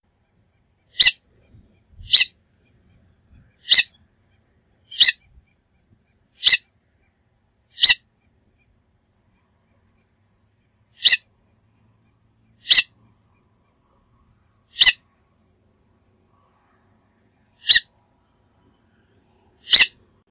Limosa haemastica - Becasina de mar
becasademar.wav